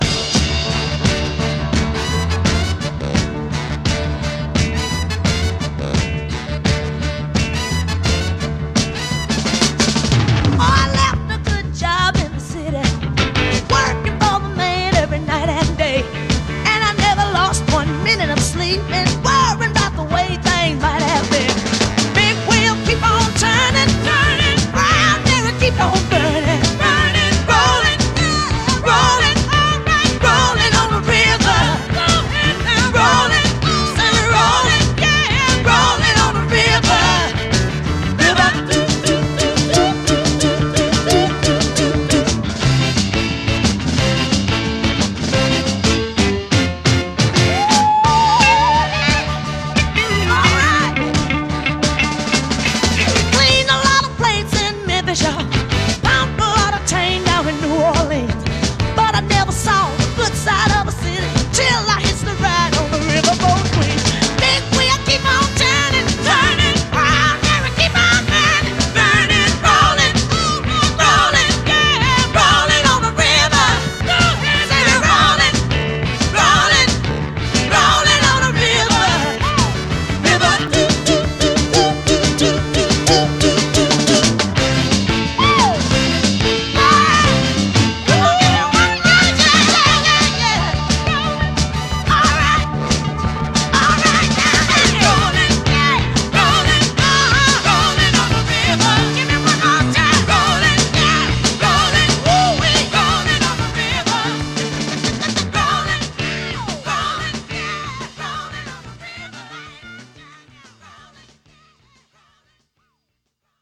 BPM170-184
MP3 QualityMusic Cut